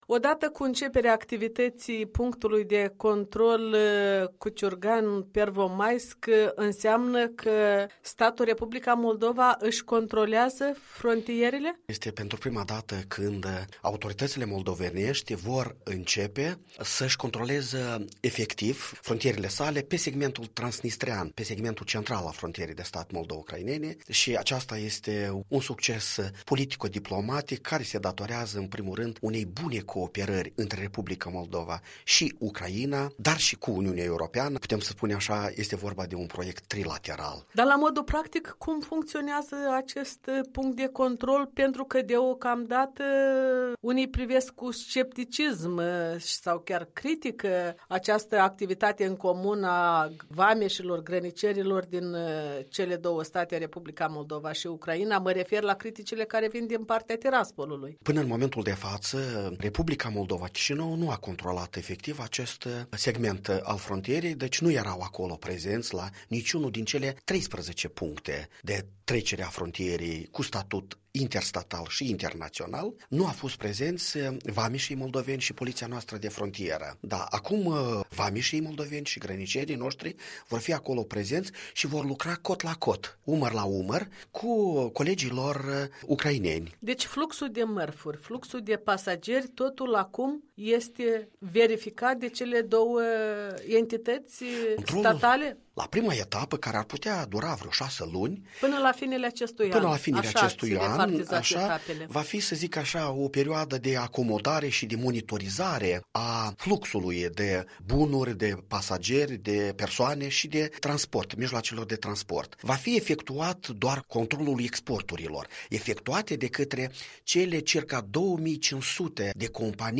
Despre beneficiile controlului mixt moldo-ucrainean și reglementarea transnistreană, în dialog cu ambasadorul cu misiuni speciale din cadrul MAEIE.